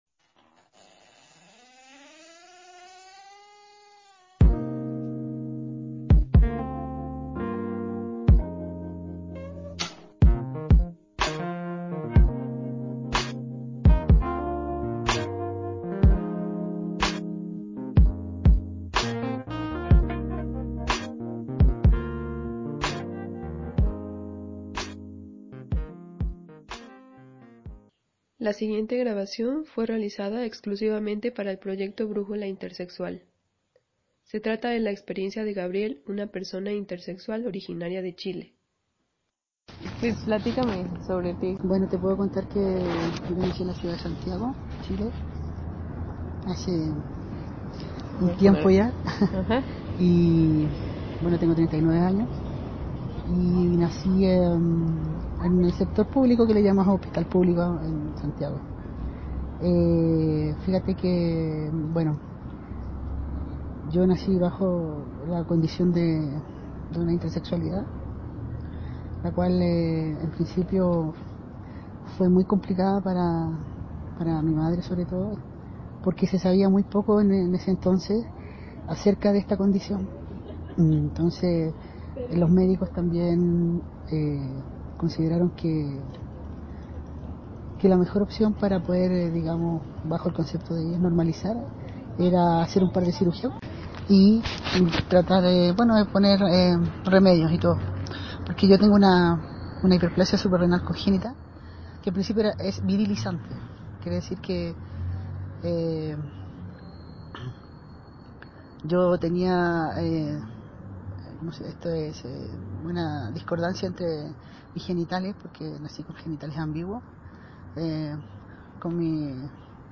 Entrevista
La música que aparece en la entrevista fue utilizada sin fines de lucro